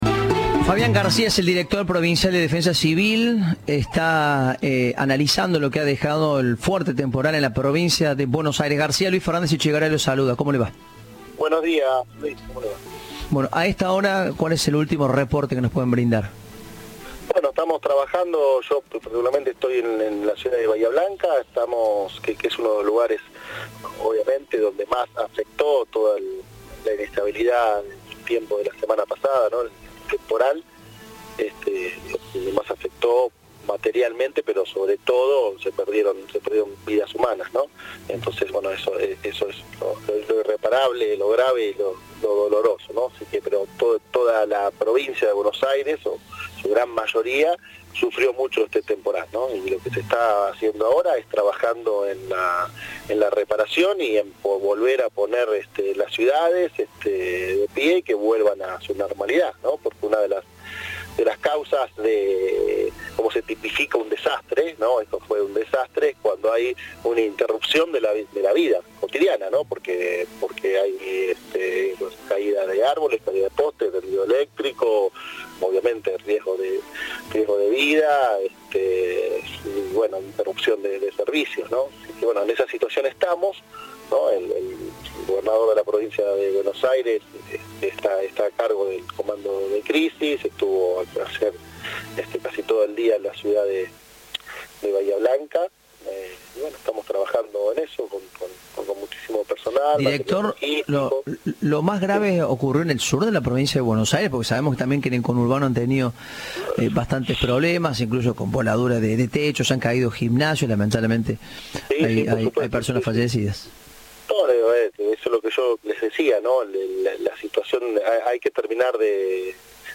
En diálogo con Cadena 3, Fabián García, director provincial de Defensa Civil de la provincia de Buenos Aires, dijo que continúan trabajando en la zona de Bahía Blanca que es uno de los lugares donde más afectó todo el temporal y donde lo irreparable son las pérdidas humanas.